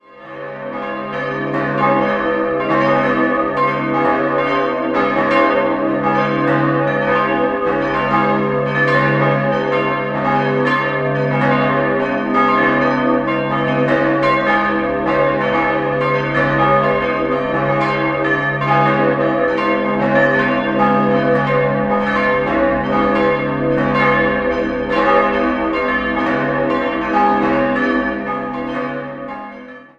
3-stimmiges Geläute: des'-as'-c''-des''-f'' Die große Glocke wurde 1618 von Melchior Moering, die zweitgrößte 1360 von Meister Hermann, die mittlere 1955 von Schilling (Apolda) und die beiden kleinen 1295 gegossen.